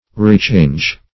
Rechange \Re*change"\ (r[=e]*ch[=a]nj"), v. t. & i. To change again, or change back.